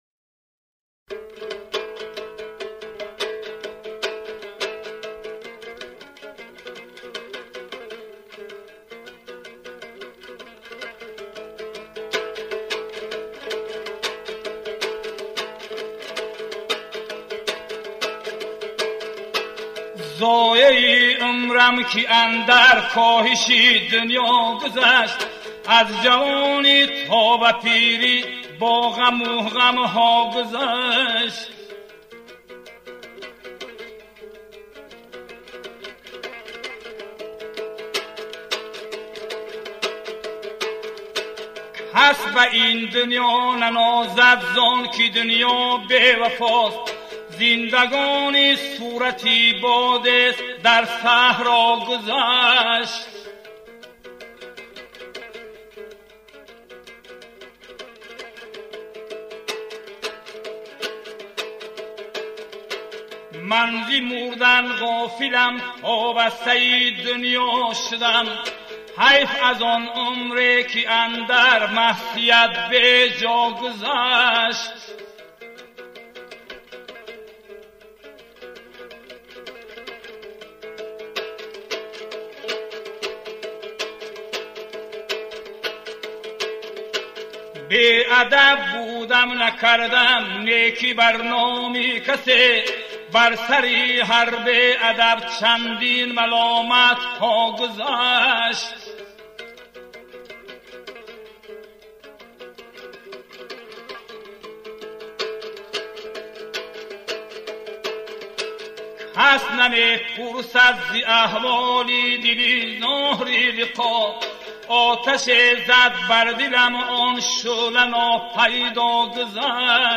Таронаҳои суннатии тоҷикӣ